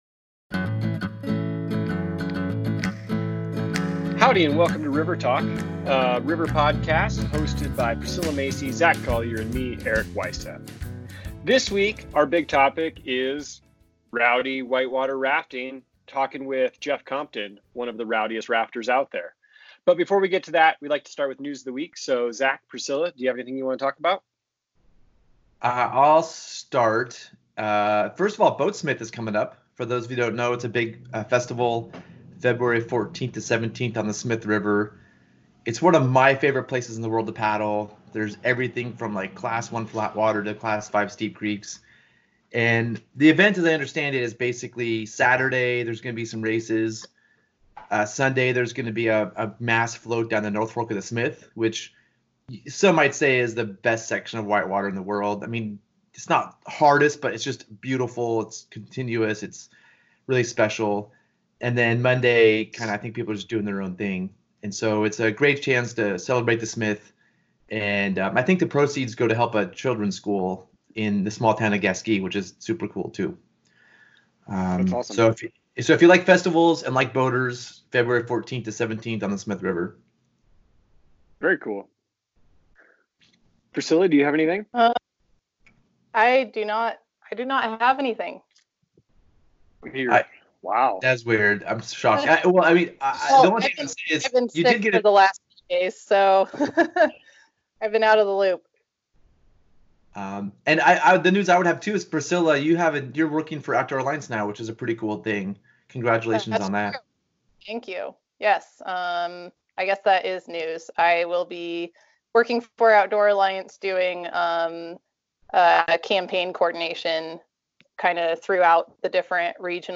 Gear Garage Live Show Ep. 67: Interview